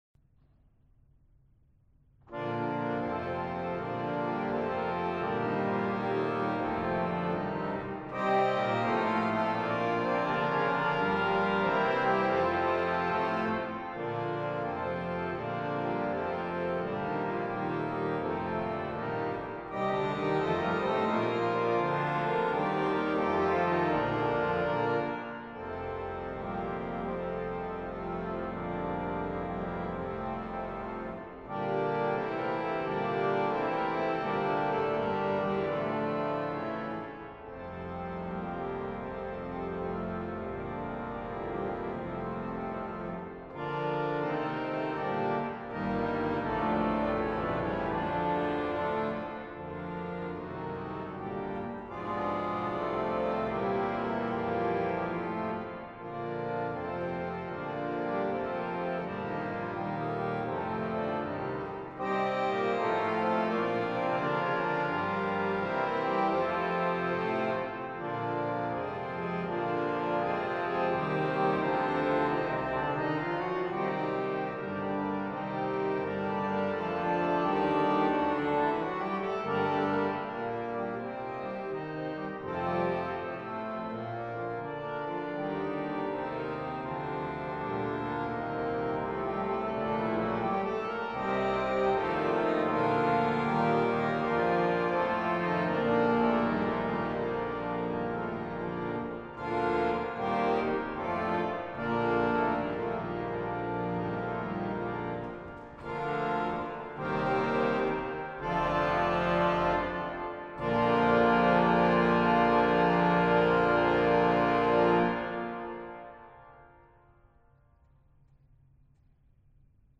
Organ / Harmonium
Notes Recorded on the 4 rank Debain harmonium (1875) at the Onze-Lieve-Vrouwekerk Amsterdam Purchase Javascript is required for this feature.